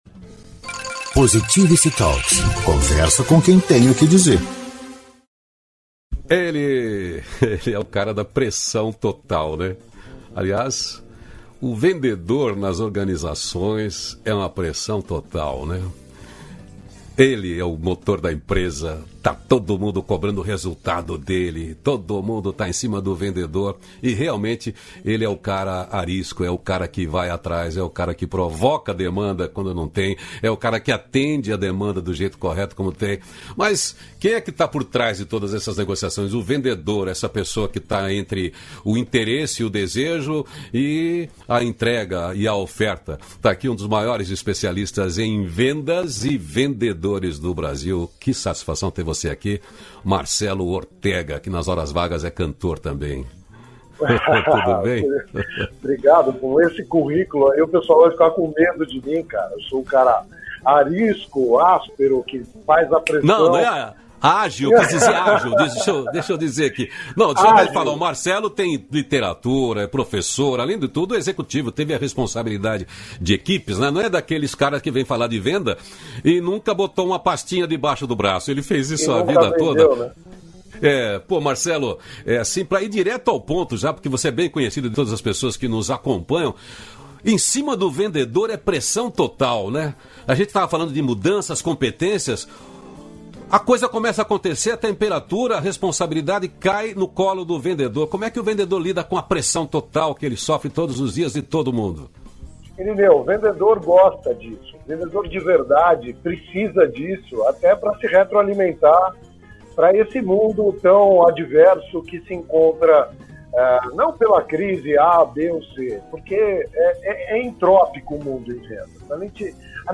236-feliz-dia-novo-entrevista.mp3